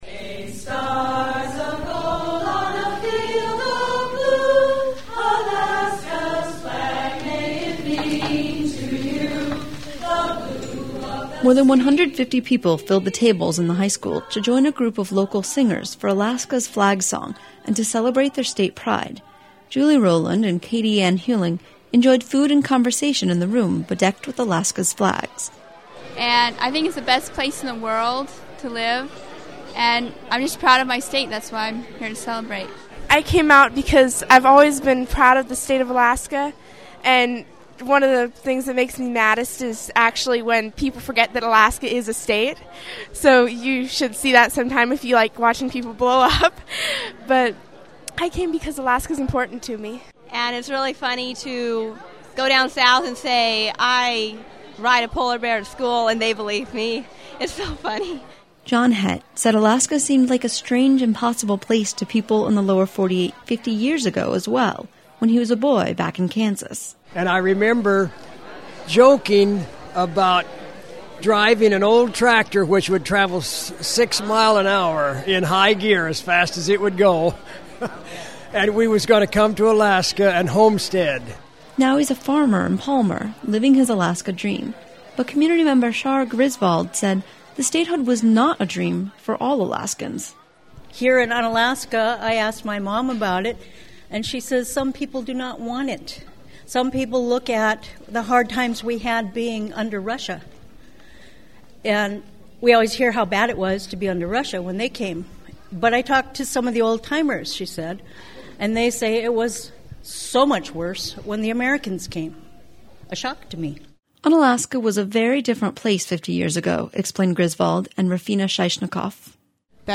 Unalaska, AK – Unalaskans gathered in the high school this weekend to celebrate the 50th Anniversary of Alaska's statehood with a potlatch and with memories of Unalaska 50 years ago.